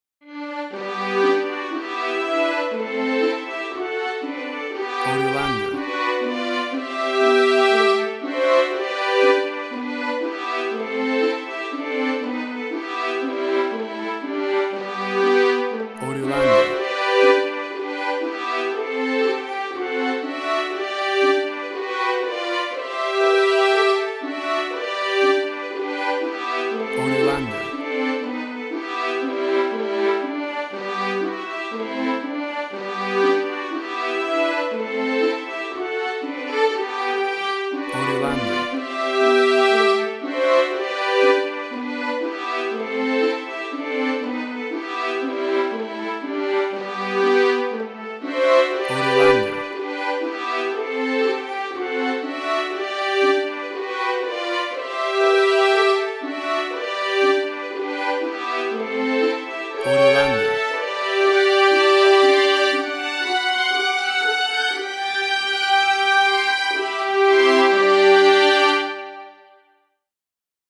emotional music
WAV Sample Rate: 16-Bit stereo, 44.1 kHz
Tempo (BPM): 118